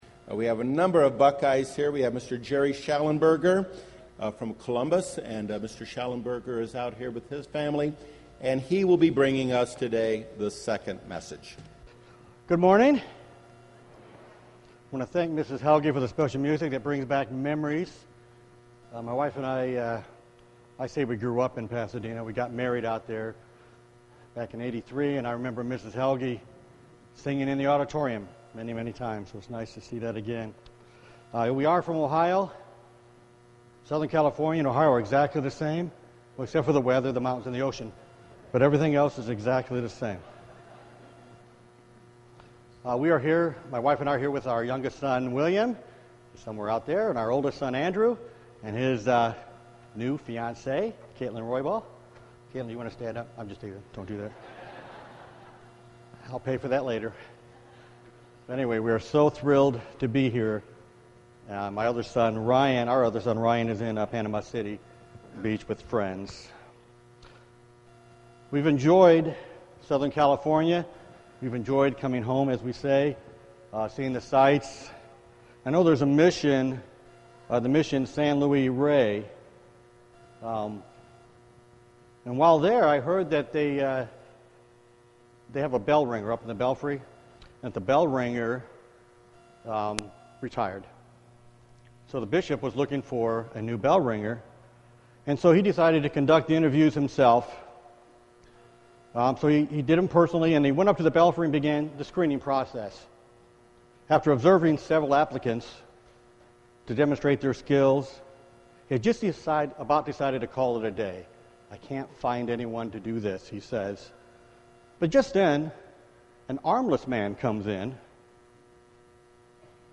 This sermon was given at the Oceanside, California 2013 Feast site.